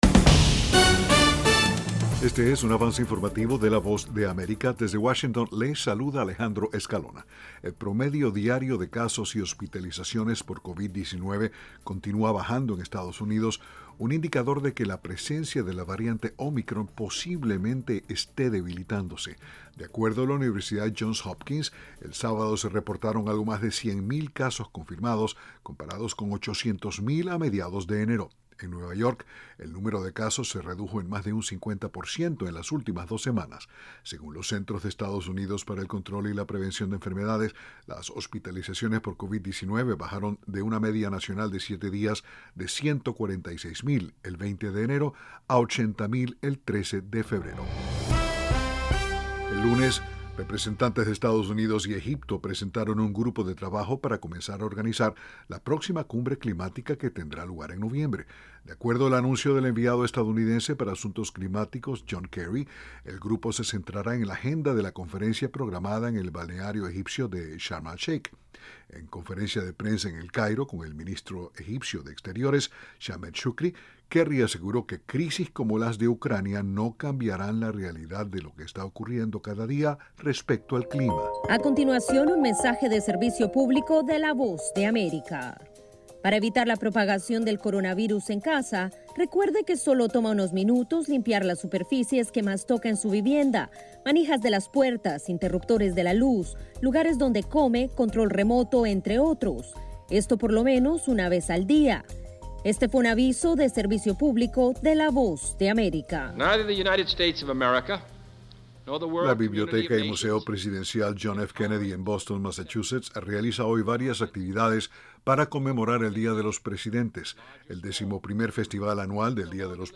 Avance Informativo
El siguiente es un avance informativo presentado por la Voz de América en Washington.